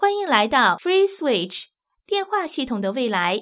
ivr-welcome_to_freeswitch.wav